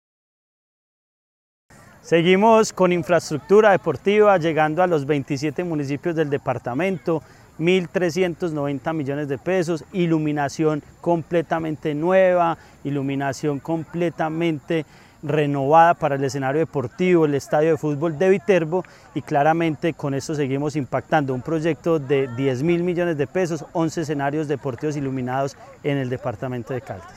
Andrés Duque Osorio, secretario de Deporte, Recreación y Actividad Física de Caldas.